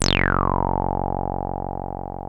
303 F#1 4.wav